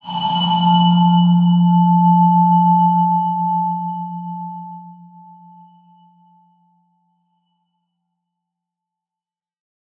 X_BasicBells-F1-mf.wav